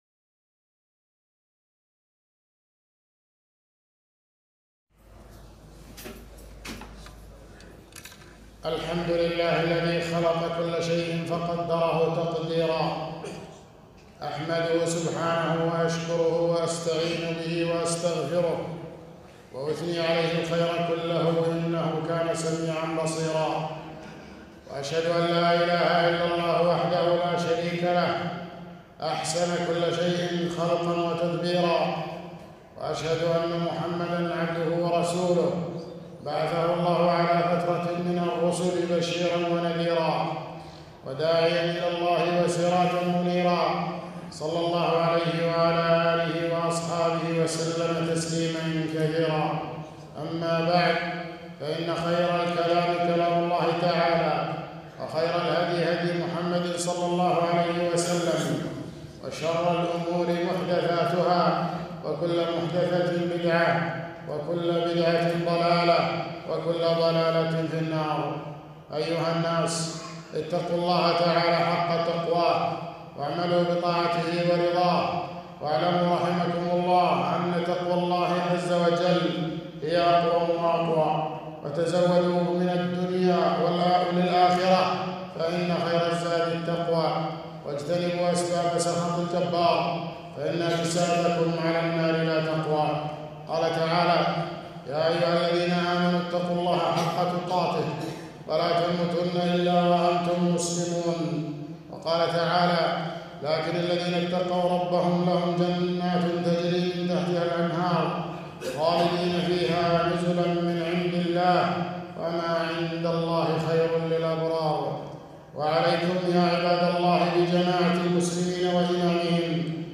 خطبة - ثمرات الإيمان بالقدر